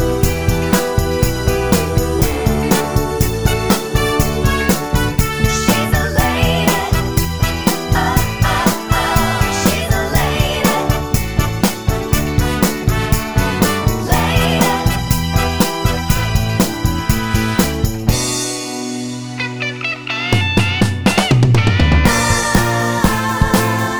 Short Ending Pop (1960s) 2:48 Buy £1.50